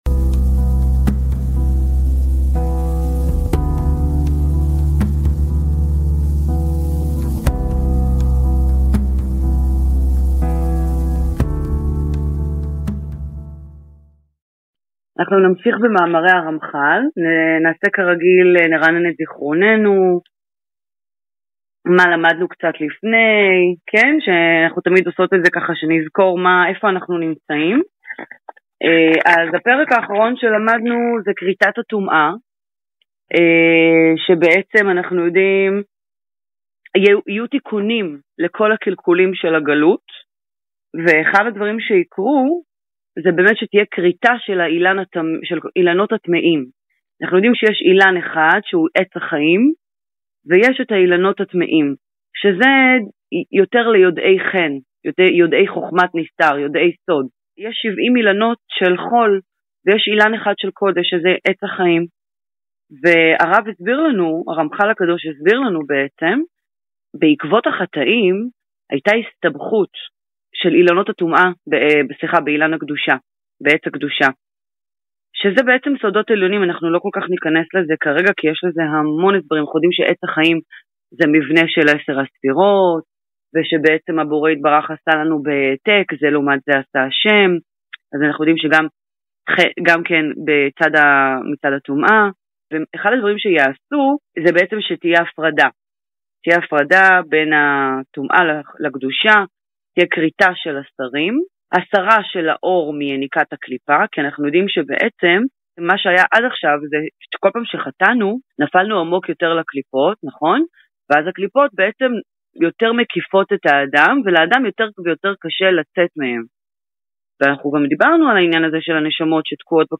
לימוד מתוך ספרו של הרמחל מאמרי גאולה בתוך קבוצת החברותא לנשים למידת התורה וחסידות דרך הזום.